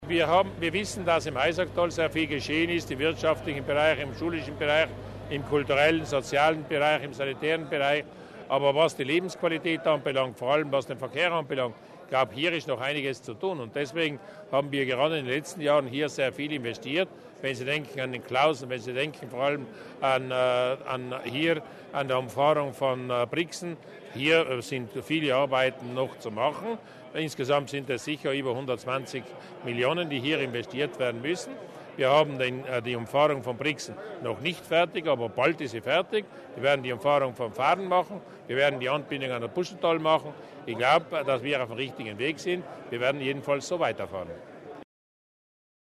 Durnwalder zum Durchstich des Südtunnels der Umfahrung von Brixen